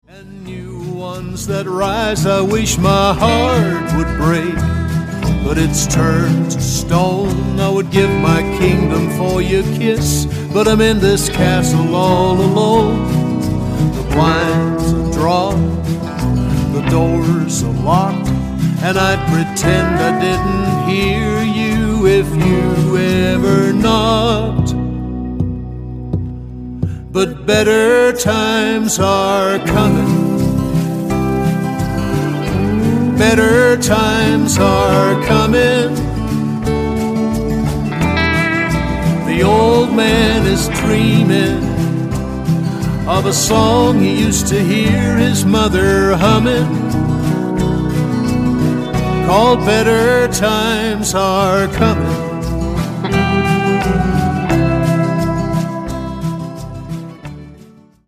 • Americana
• Country
• Folk
• Singer/songwriter